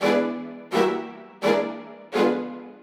Index of /musicradar/gangster-sting-samples/85bpm Loops
GS_Viols_85-E.wav